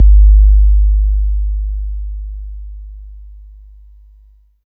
43 808 KICK.wav